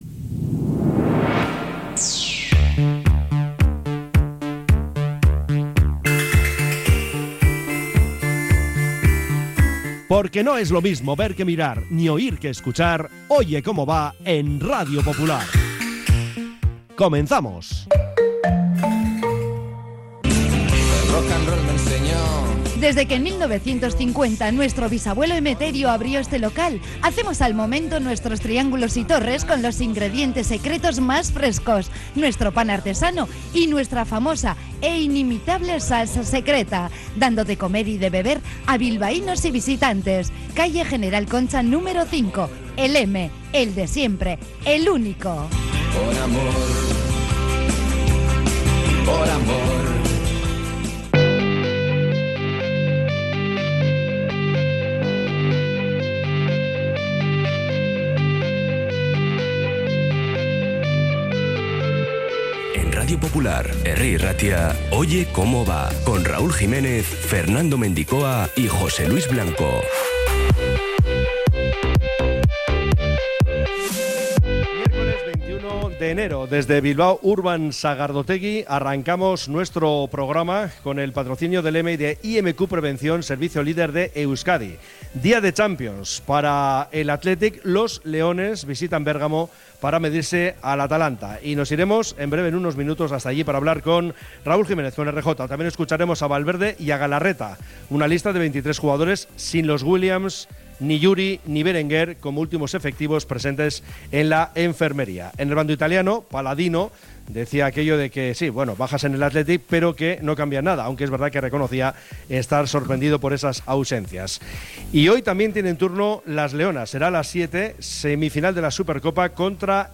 Tramo informativo de 13.30 a 14h